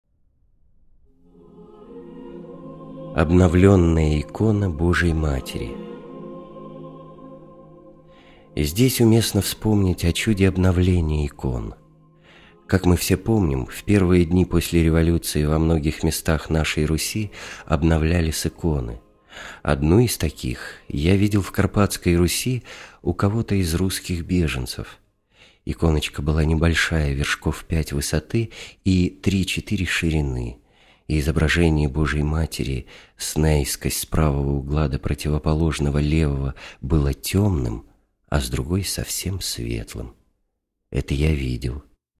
Аудиокнига: митр. Вениамин (Федченков) "Промысел Божий в моей жизни"